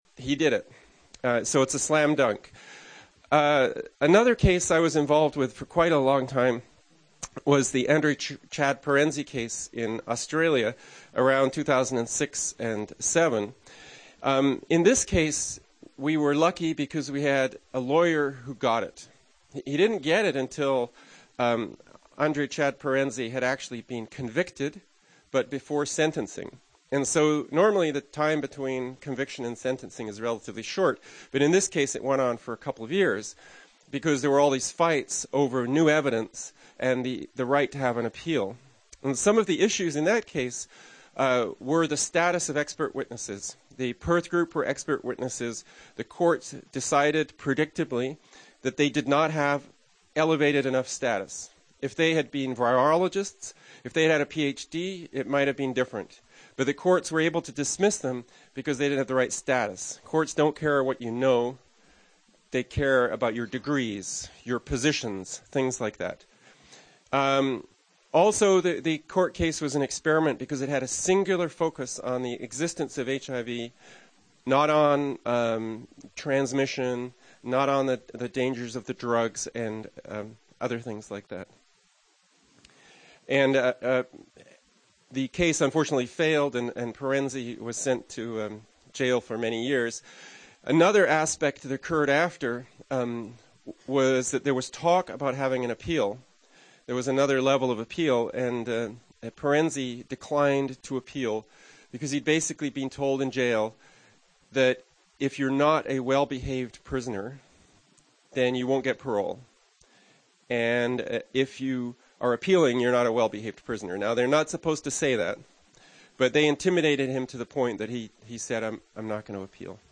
Waterfront Plaza Hotel, Oakland
2. Speech